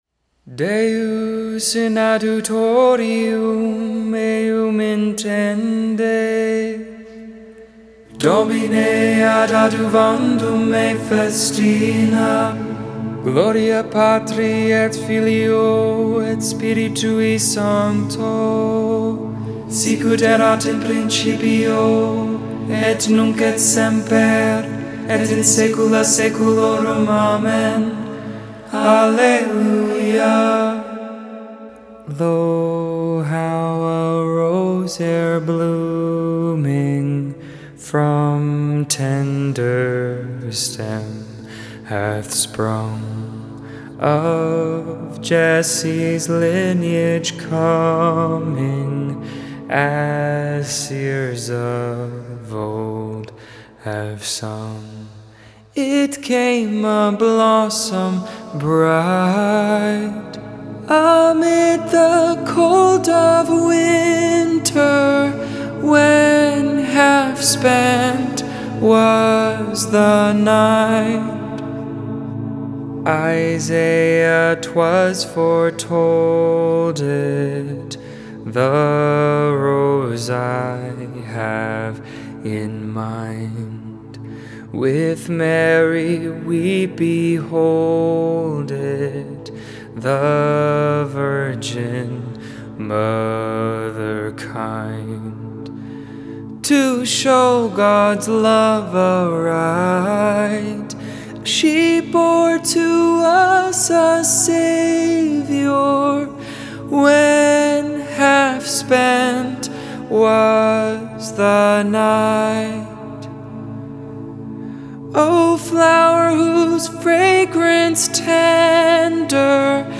Saturday Vespers I, the 4th Sunday in Advent.